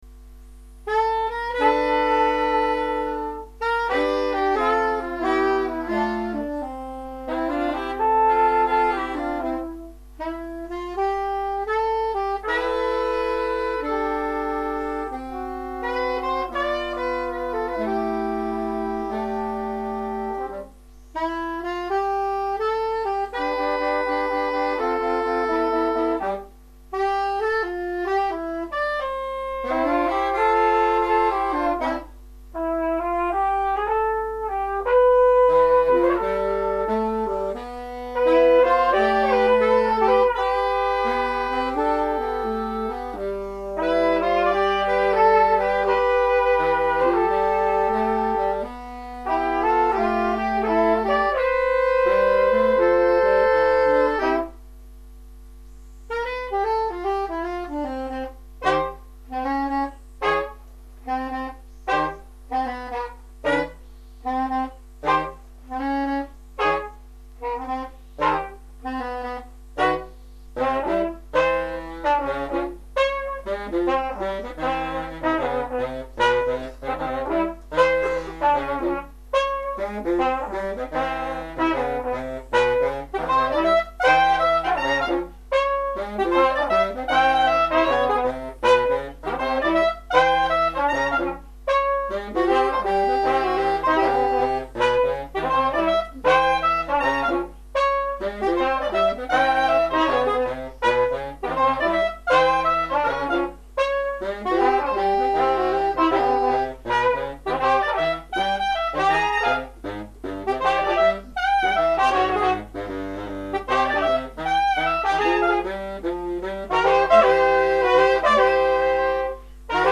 ss, bars, tp/flh, !perf
· Genre (Stil): Jazz